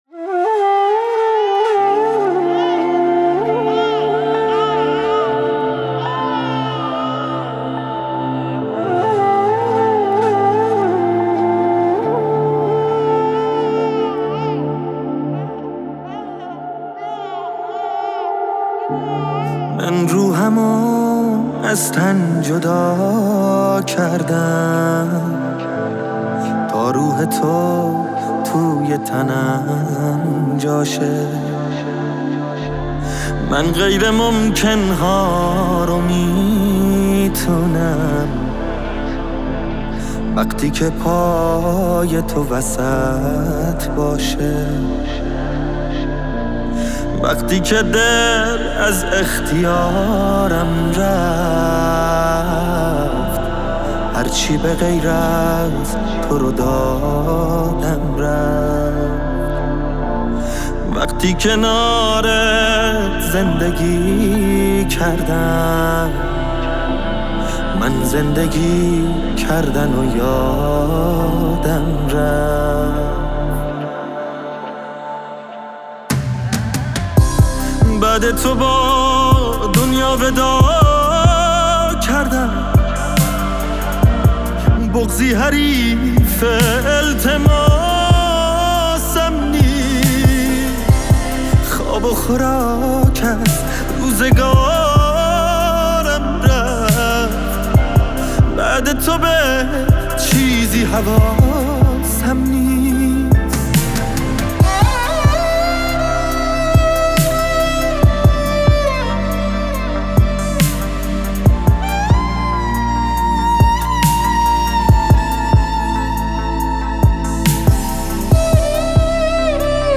خواننده پاپ
خواننده موسیقی پاپ